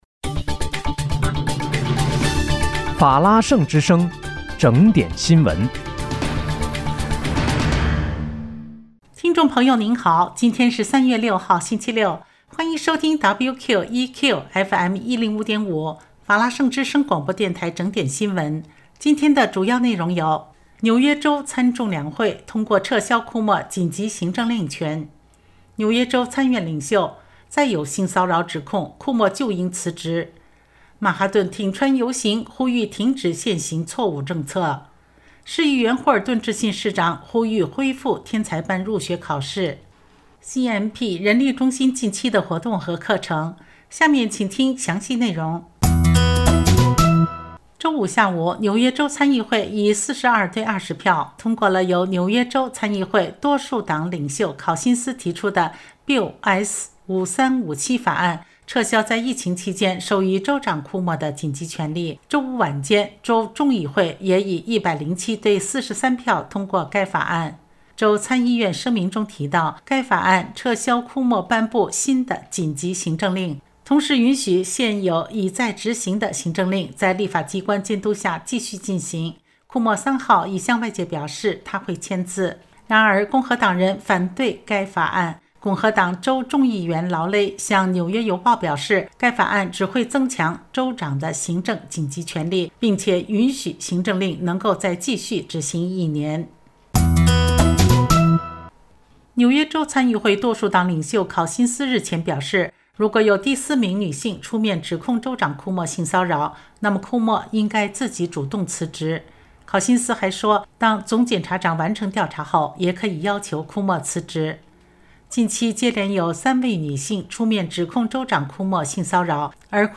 3月6日（星期六）纽约整点新闻